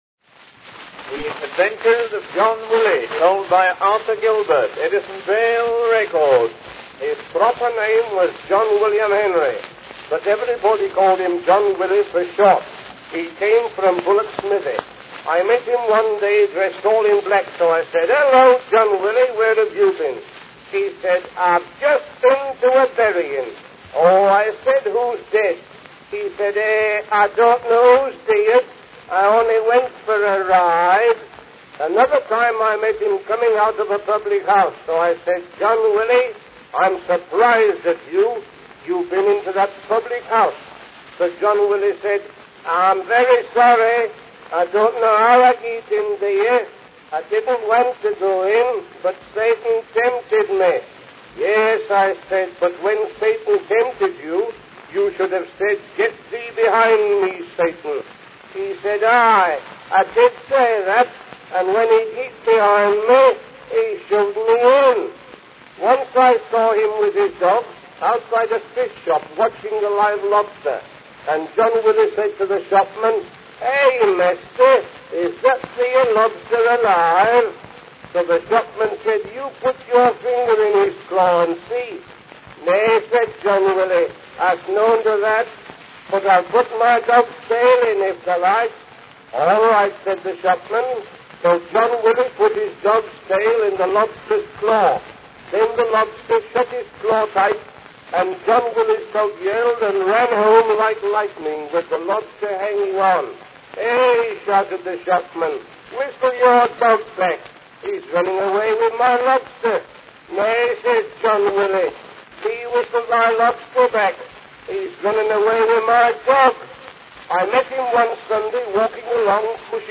Die Goldguss-Walzen werden mit einer Drehzahl von 120 U/min gespielt und haben eine Spieldauer von 2 Minuten.
Wie sich eine Goldguss-Walze anhört: